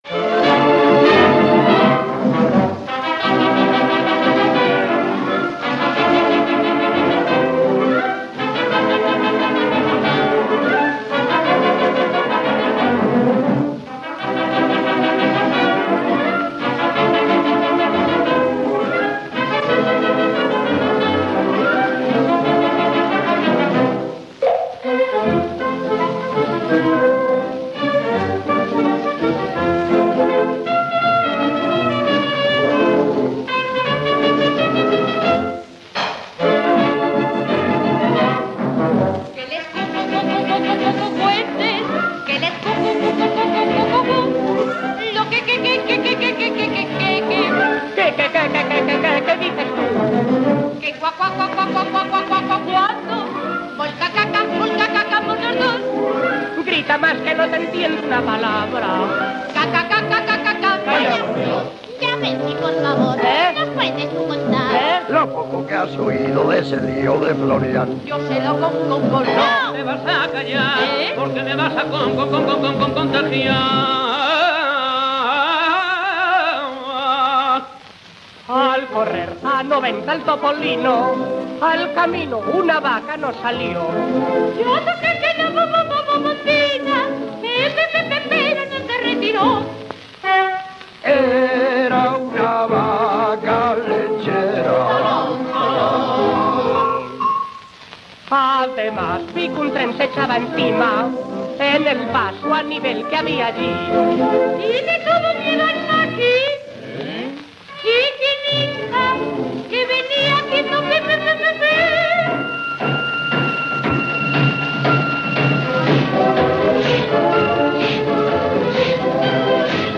Rumba
orquesta